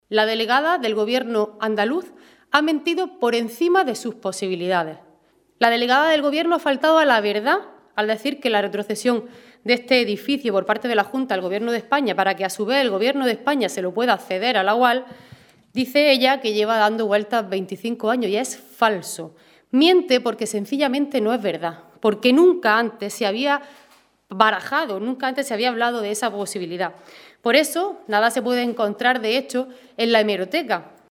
En rueda de prensa, la diputada socialista ha calificado de “intolerable” que el Partido Popular haya tenido “la desvergüenza” de “ponerle tareas” a la Universidad de Almería para que pueda conseguir su facultad de Medicina, al tiempo que el Gobierno andaluz “se mantiene callado o, incluso, llega a decir lo contrario, como ha hecho el consejero de Universidades, Rogelio Velasco, al sugerir que existe un exceso de cargos y titulaciones”.